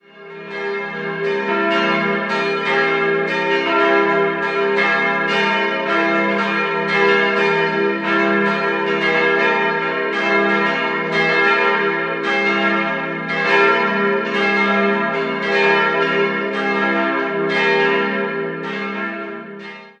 Idealquartett e'-g'-a'-c'' Die Glocken wurden 1957 von der Gießerei Bachert hergestellt.